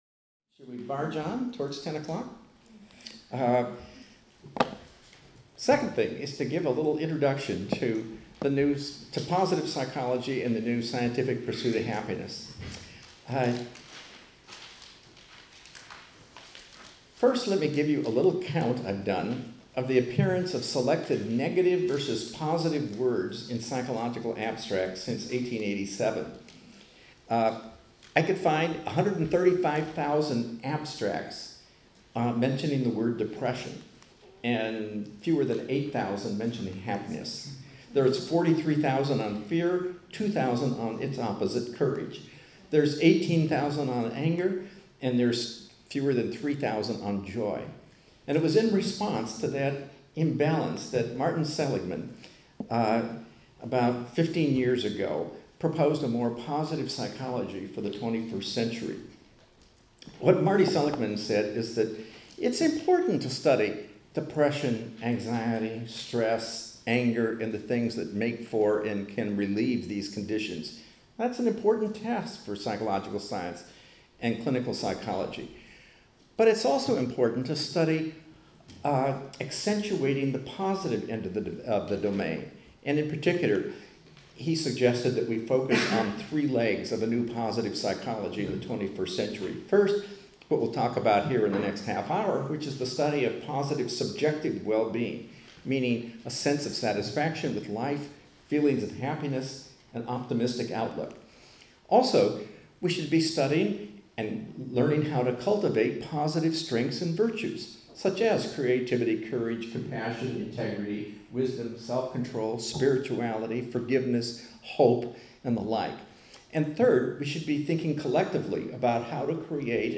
Nå legger vi også endelig ut lydfilene og lysbildene fra seminarene.